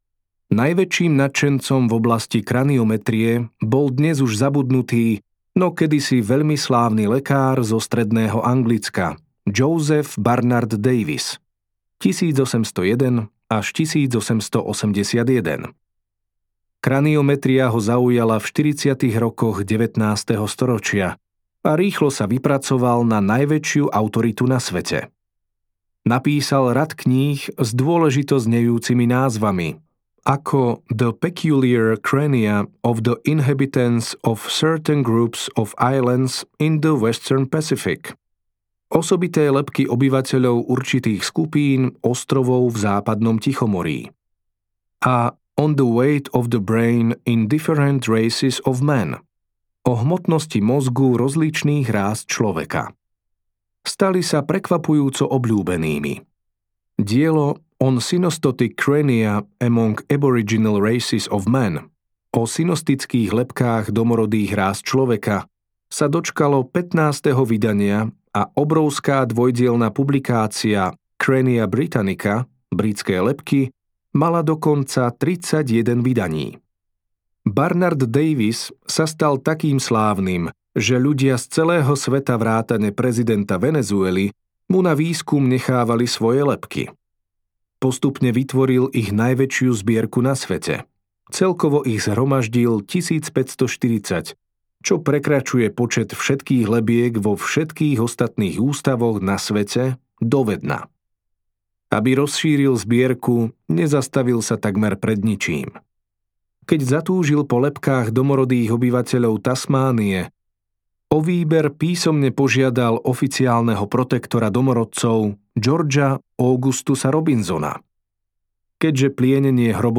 Ľudské telo audiokniha
Ukázka z knihy